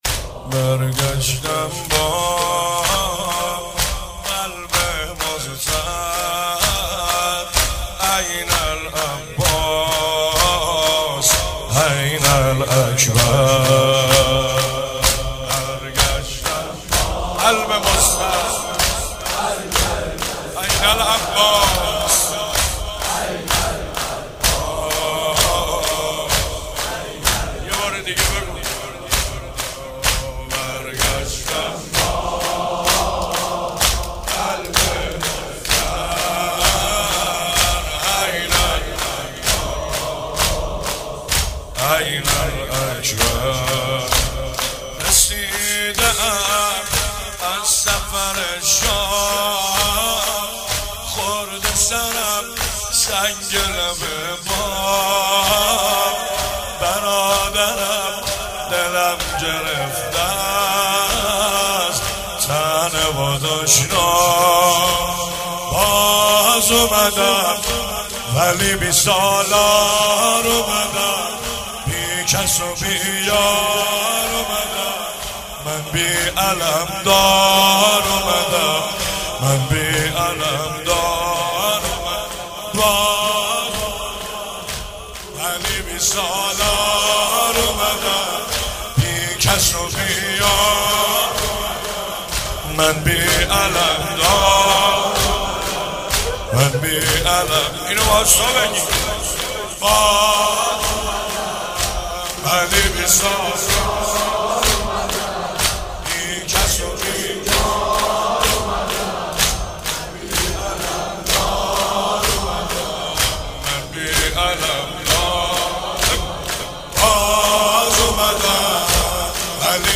شب سوم رمضان 95، حاح محمدرضا طاهری
03 heiat alamdar mashhad.mp3